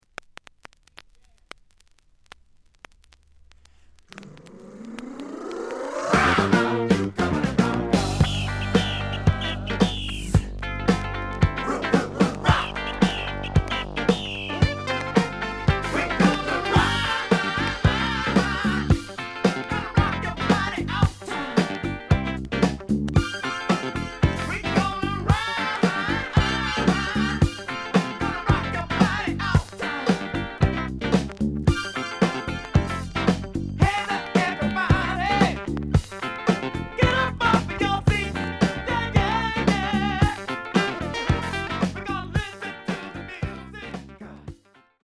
The audio sample is recorded from the actual item.
●Genre: Disco
Some click noise on both sides due to scratches.)